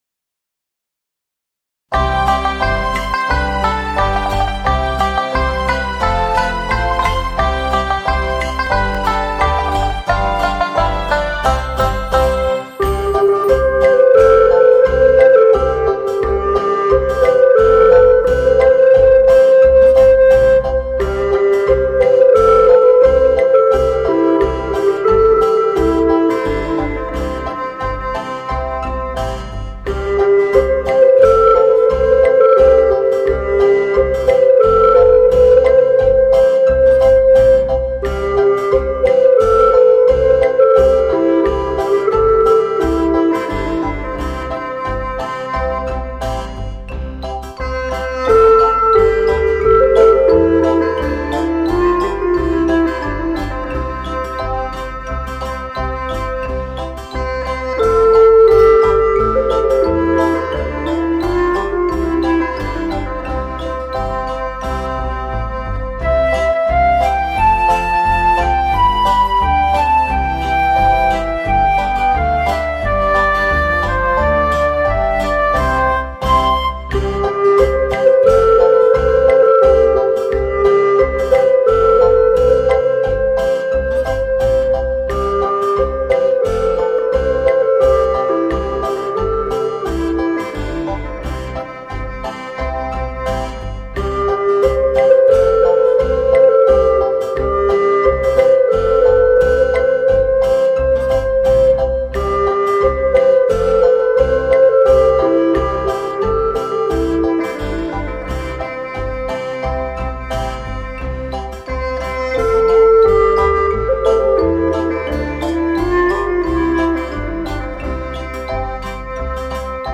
这两张CD是用巴乌对一些我们熟悉曲目的重新演绎。
不高不低的音乐，适合现在在听音乐的你我！